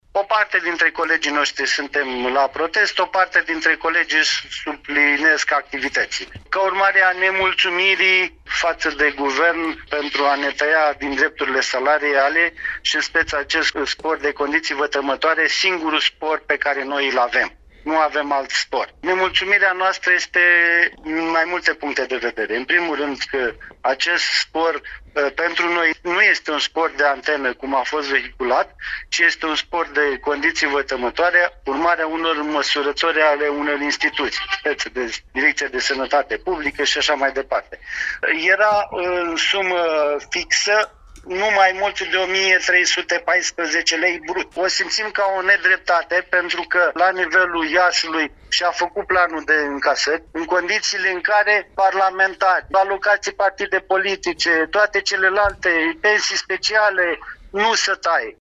(AUDIO/FOTO) Iaşi: Protest spontan al sindicaliştilor ANAF